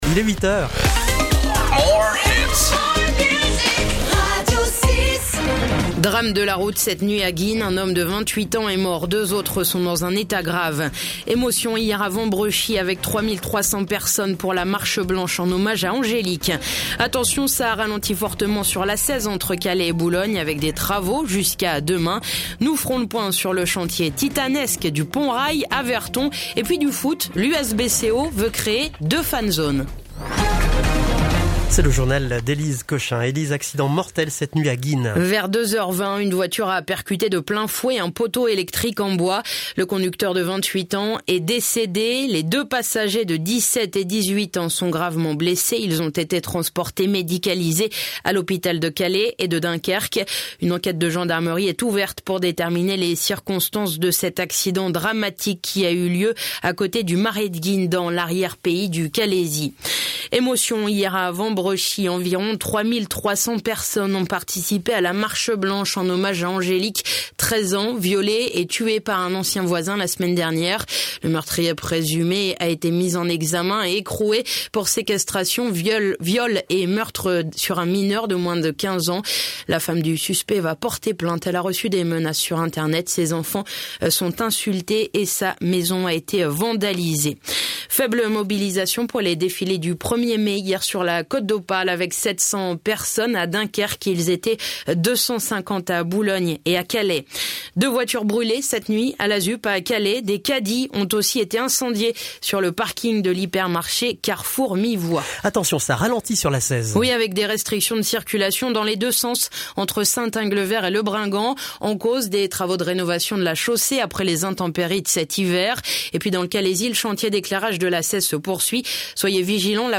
Le journal Côte d'Opale du mercredi 2 mai